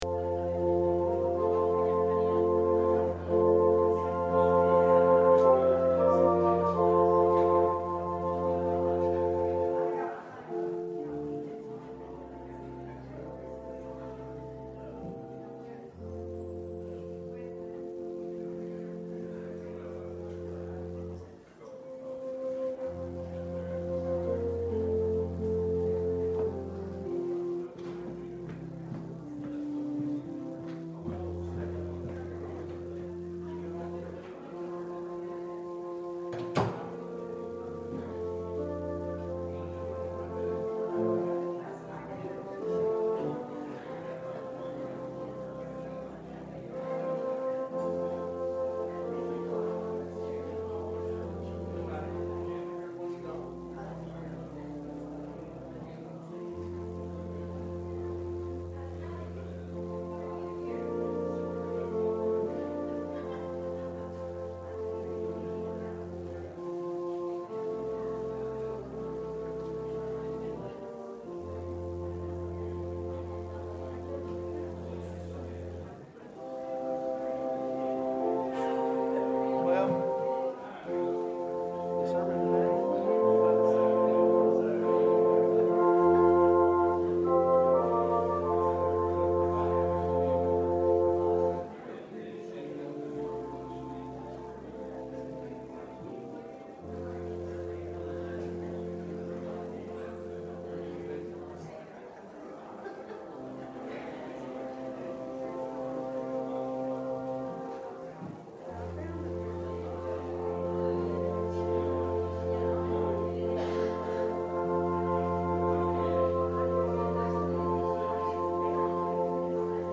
13 Service Type: Sunday Worship Are You Ready?